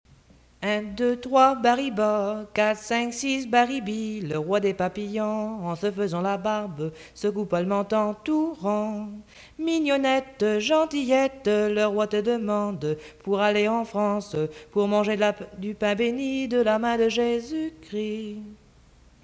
Chanson Item Type Metadata
Emplacement Saint-Pierre